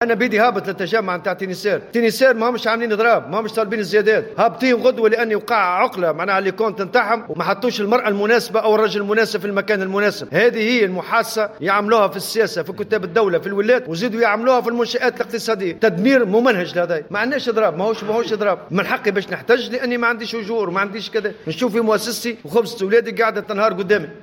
وقال الأمين العام لاتحاد الشغل في تصريح لوكالة تونس إفريقيا لانباء على هامش ندوة الأطباء الجامعيين والصيادلة وأطباء الأسنان التي نظمها الاتحاد اليوم بالعاصمة، إنه سيحضر غدا شخصيا التجمع العام لأعوان وموظفي شركة الخطوط التونسية نافيا أن يكون هناك إضراب في الشركة.